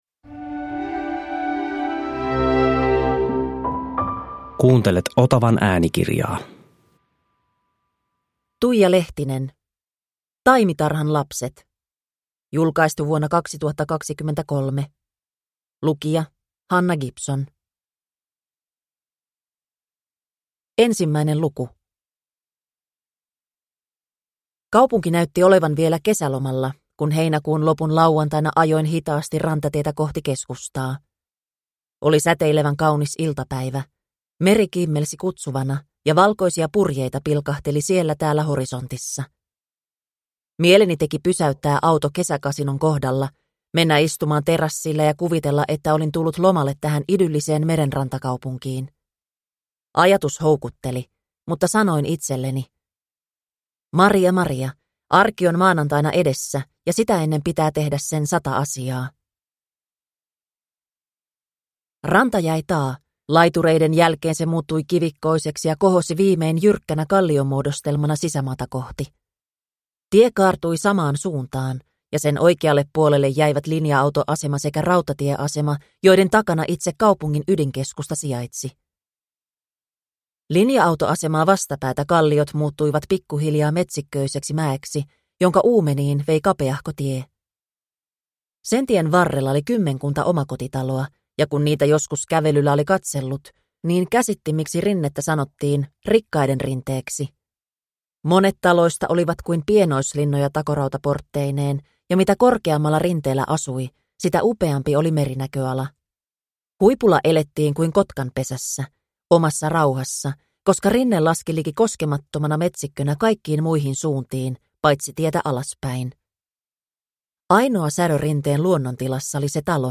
Taimitarhan lapset – Ljudbok – Laddas ner